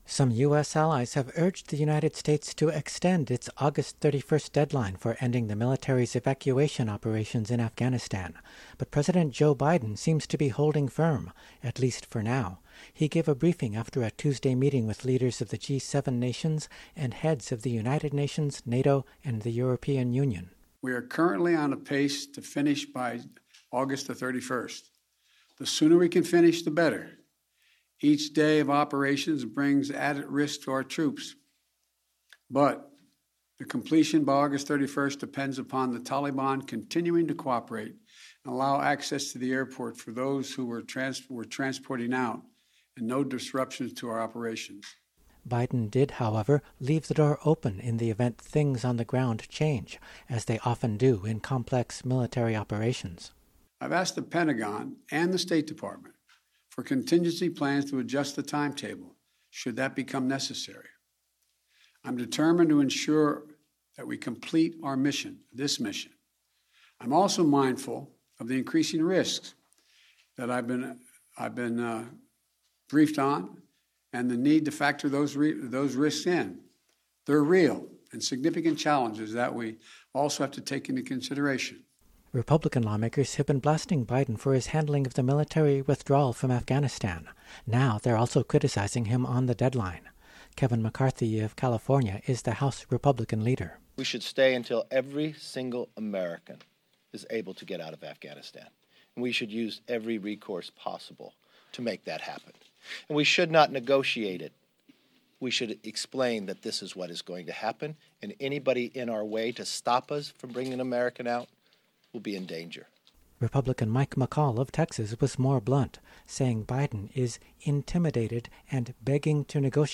Comprehensive coverage of the day’s news with a focus on war and peace; social, environmental and economic justice.